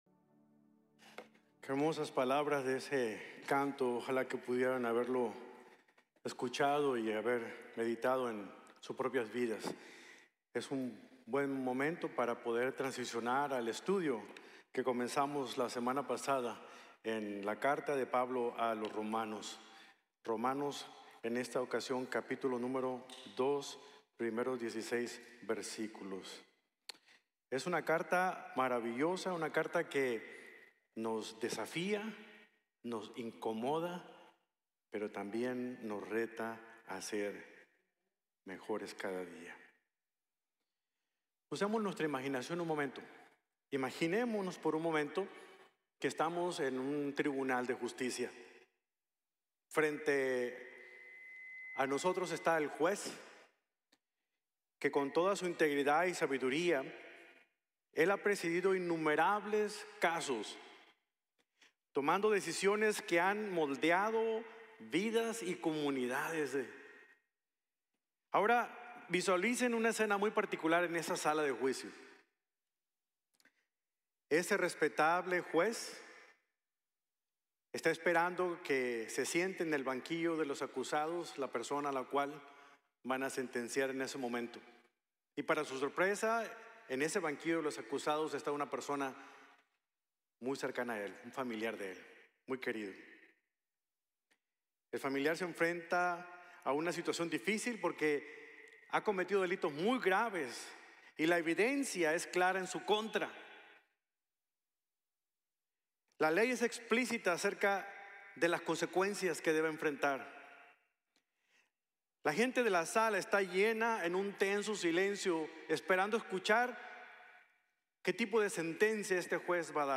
Sin excusas en el juicio de Dios | Sermon | Grace Bible Church